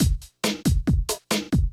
drums.wav